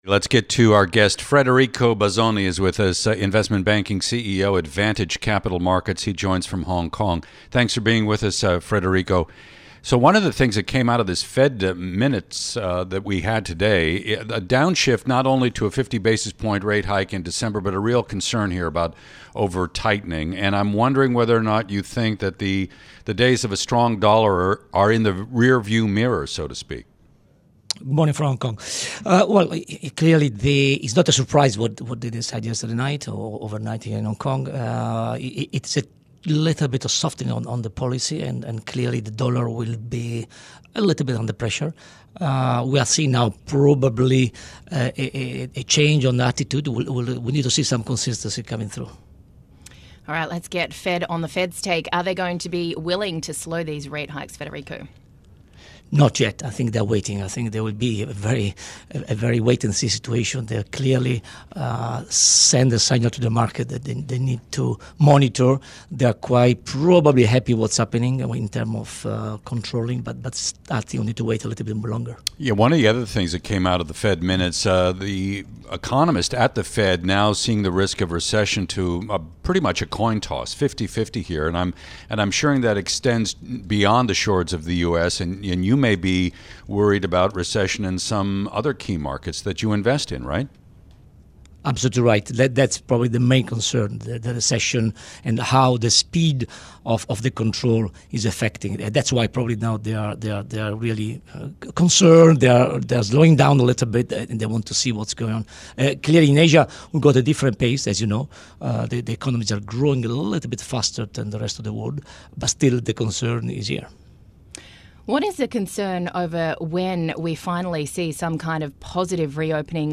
Radio) - Bloomberg Daybreak: Asia Edition